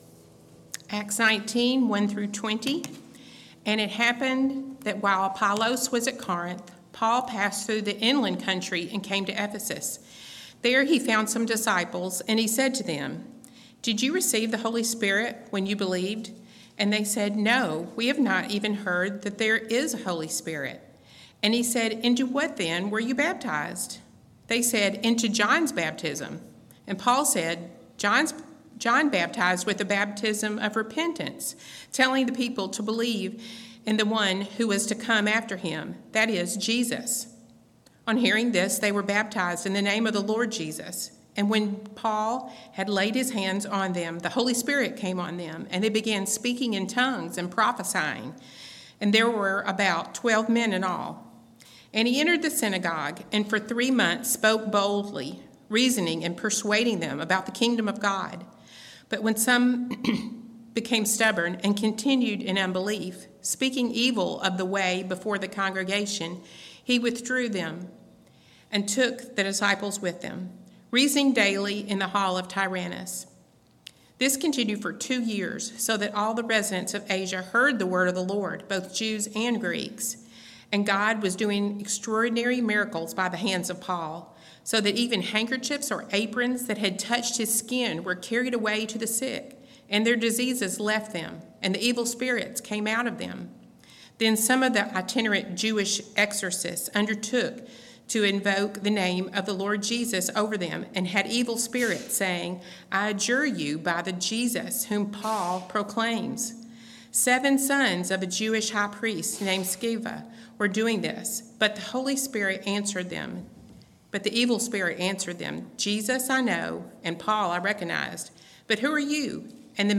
March 3 Worship Audio – Full Service
Service Type: Morning Worship